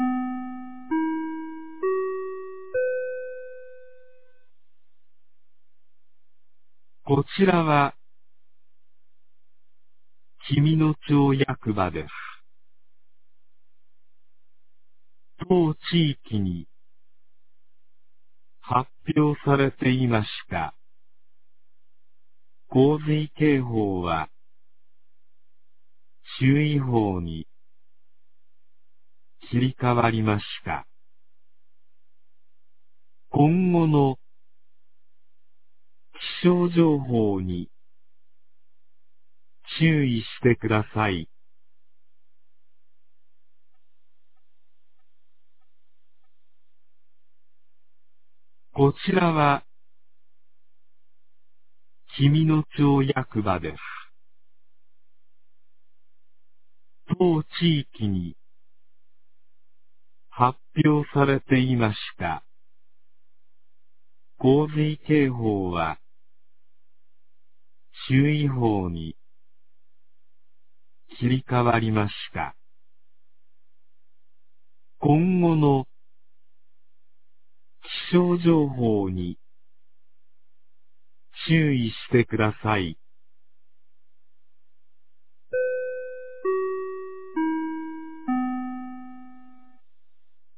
2023年06月03日 06時14分に、紀美野町より全地区へ放送がありました。